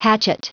Prononciation du mot hatchet en anglais (fichier audio)
Prononciation du mot : hatchet